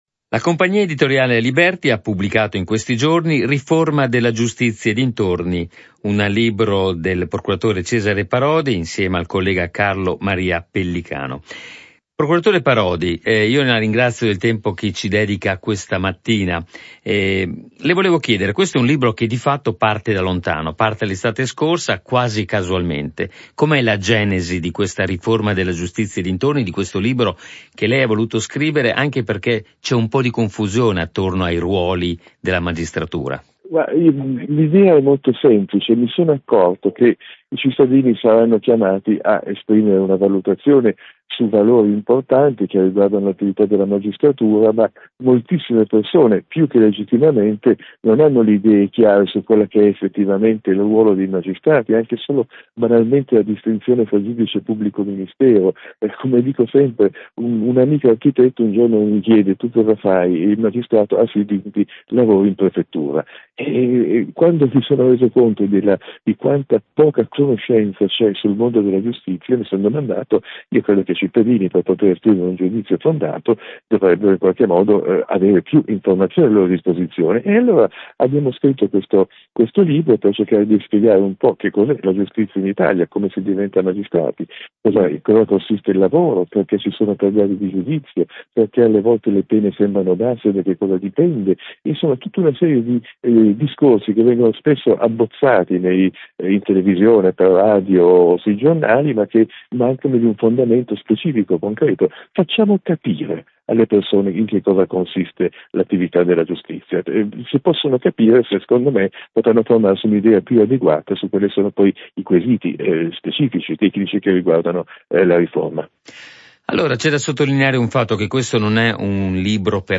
ha intervistato il Presidente Cesare Parodi: